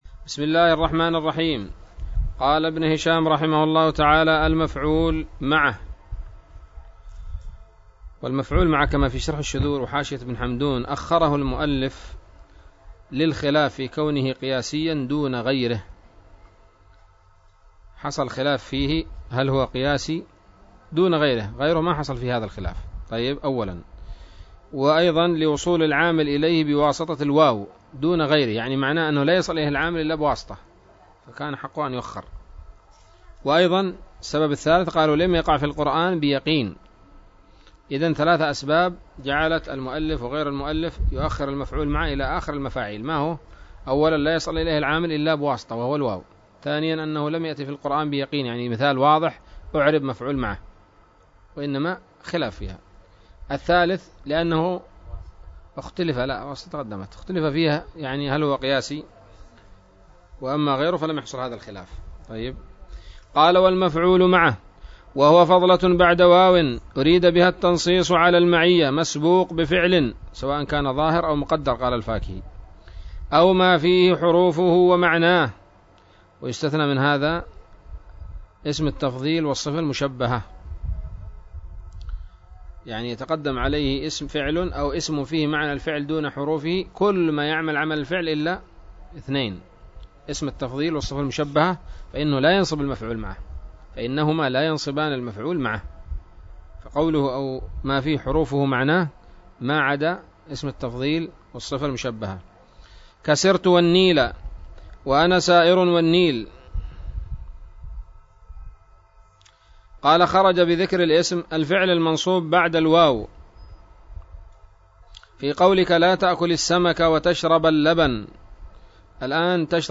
الدرس السادس والتسعون من شرح قطر الندى وبل الصدى